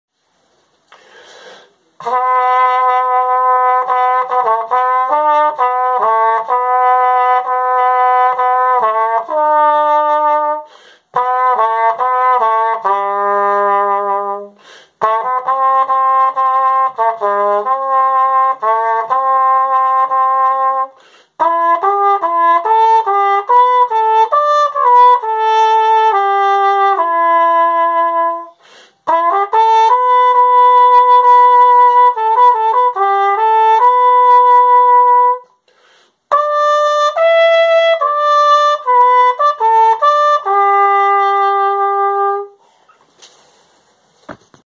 Гимн Камбоджи исполненный на трубе